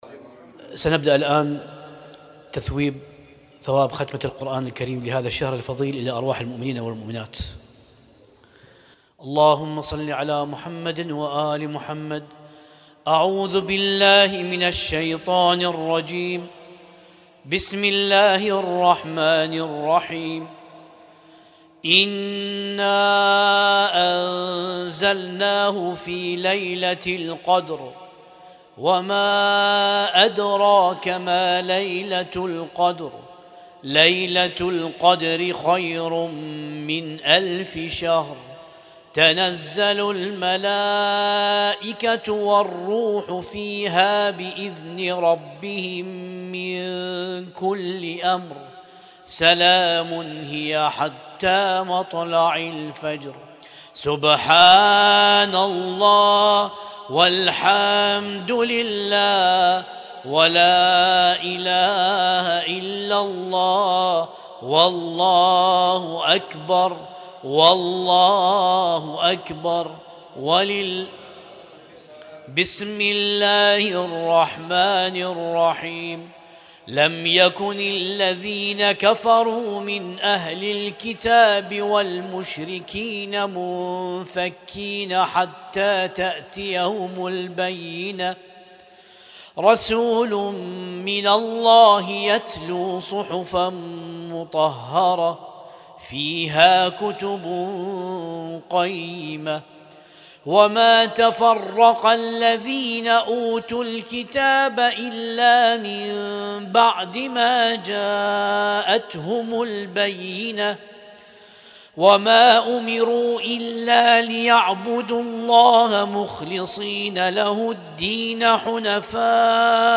Husainyt Alnoor Rumaithiya Kuwait
اسم التصنيف: المـكتبة الصــوتيه >> القرآن الكريم >> القرآن الكريم 1447